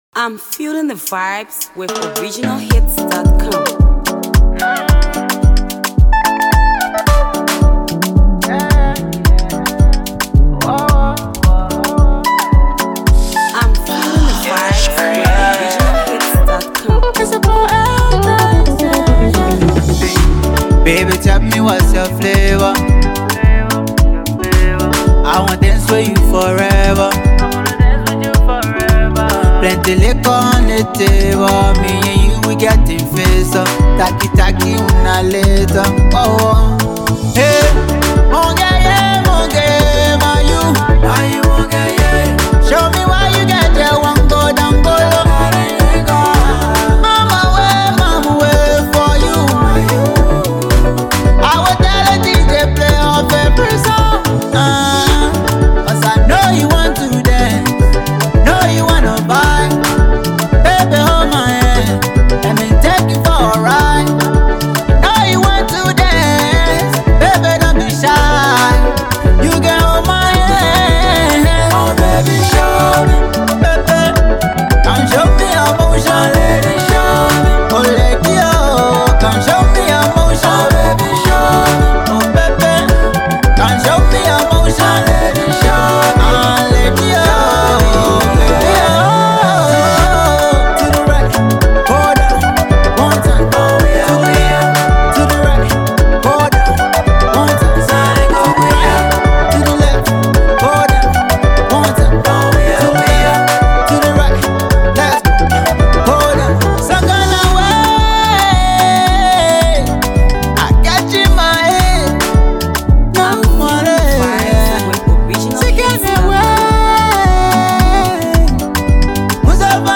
vocalist artist